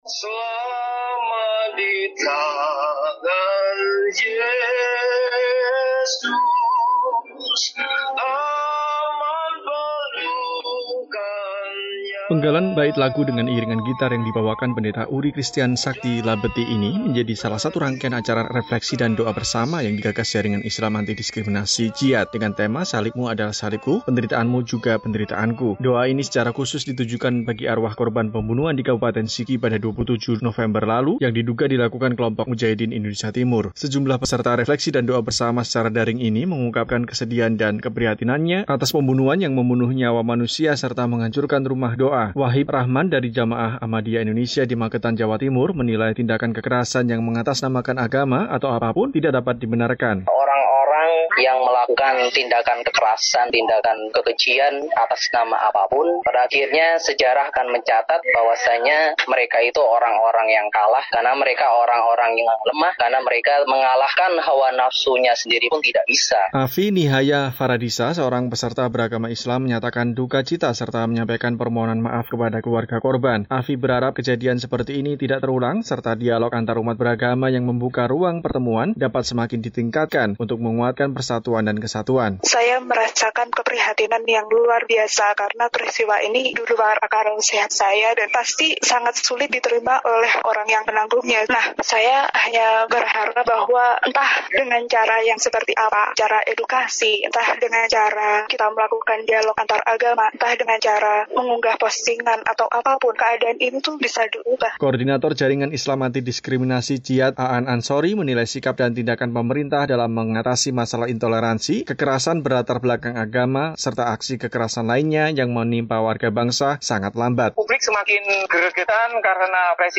Tragedi pembunuhan empat orang di Kabupaten Sigi, Provinsi Sulawesi Tengah pekan lalu melukai rasa kemanusiaan. Sejumlah orang dari lintas iman menggelar refleksi dan doa bersama untuk arwah para korban, serta untuk kedamaian di Indonesia.
Sejumlah peserta refleksi dan doa bersama secara daring ini mengungkapkan kesedihan dan keprihatinannya, atas pembunuhan dan penghancuran rumah doa.